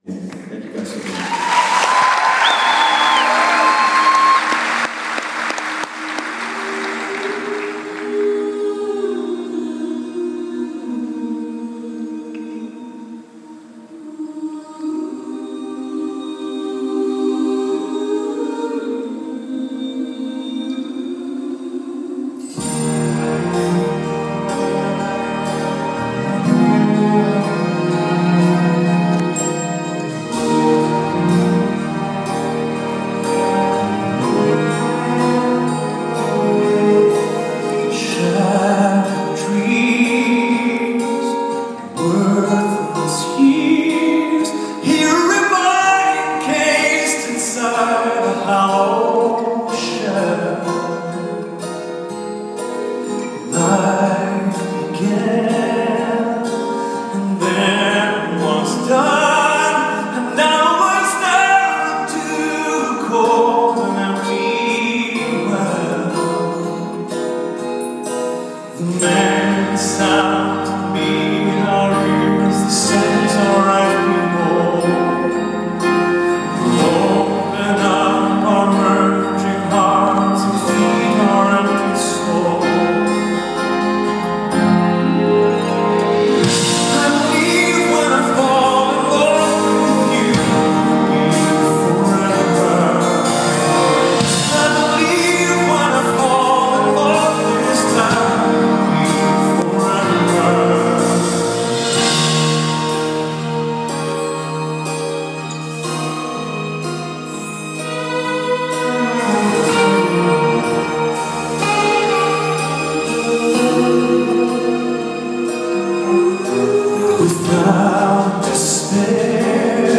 Concert